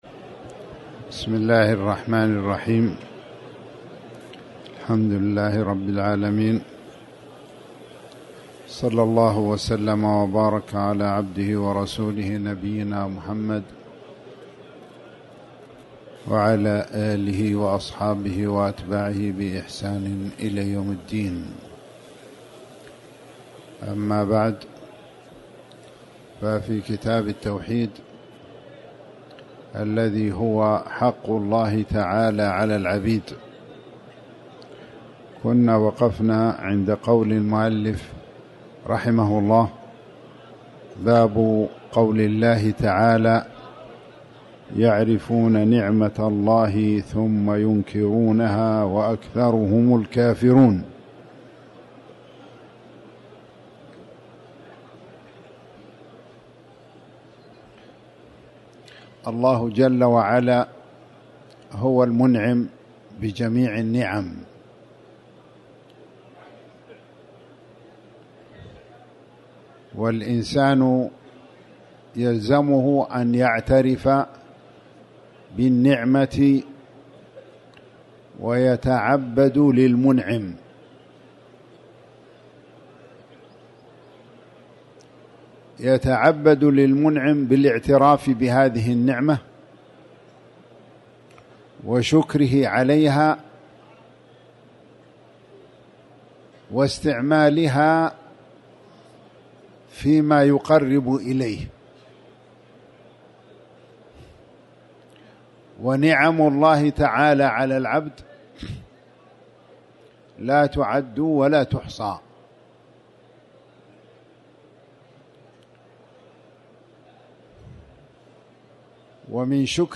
تاريخ النشر ٢٨ رمضان ١٤٤٠ هـ المكان: المسجد الحرام الشيخ